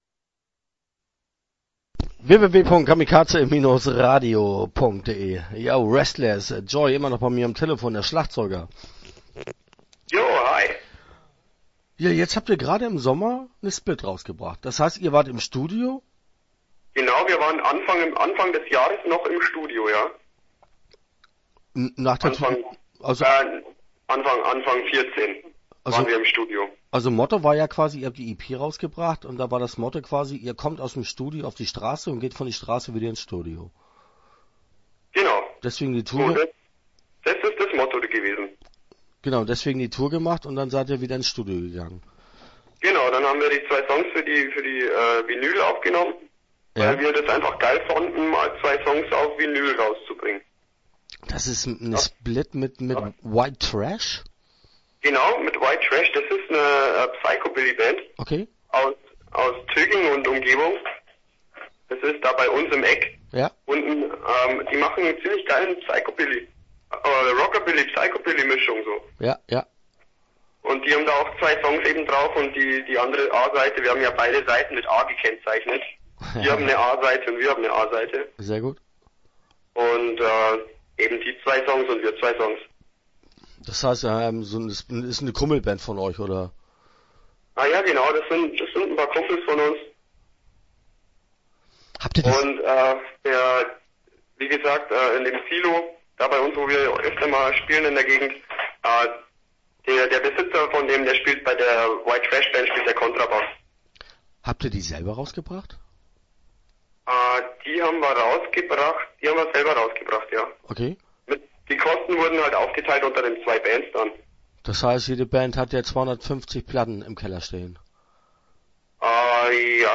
Restless - Interview Teil 1 (11:34)